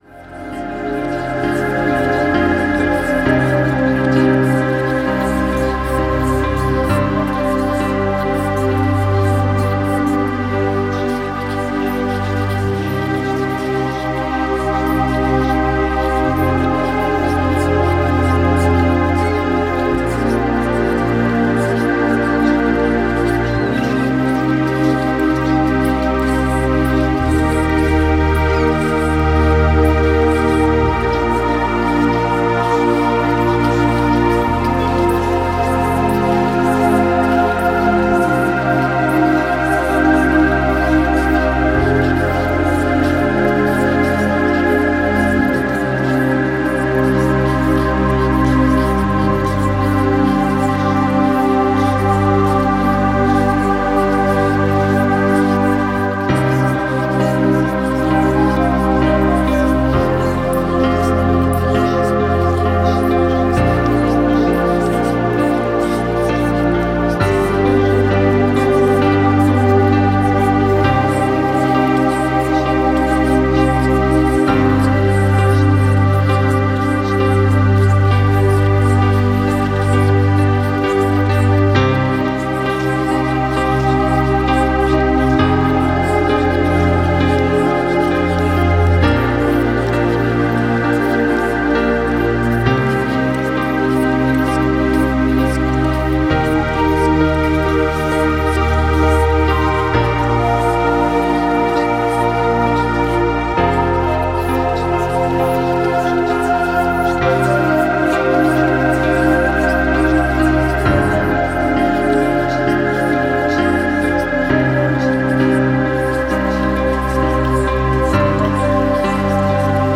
Netzwerkmarketing - Silentalk Botschaften für Ihr Unterbewusstsein - Hörbuch